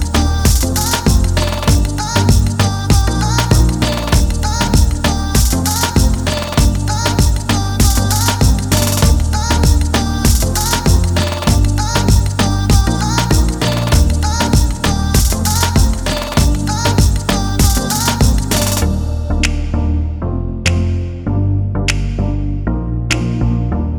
no Backing Vocals Dance 2:59 Buy £1.50